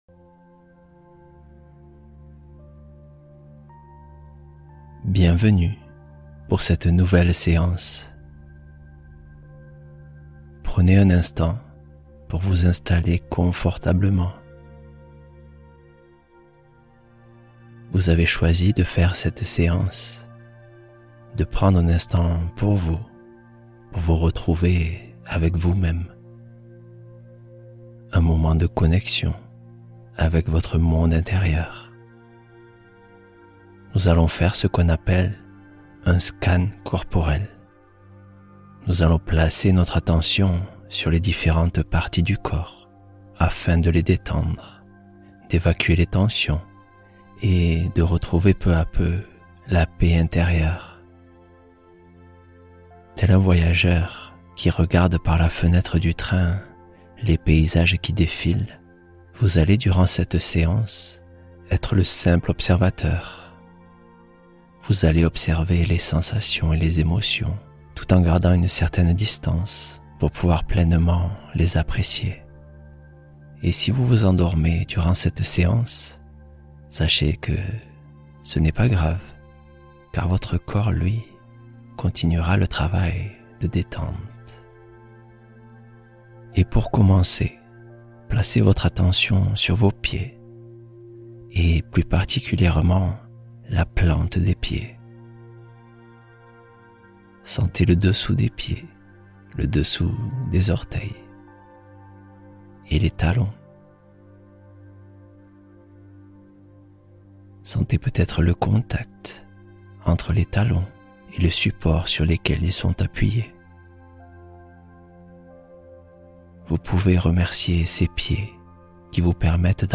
Libérez Toutes Vos Tensions En Une Séance | Scan Corporel + Affirmations De Lâcher-Prise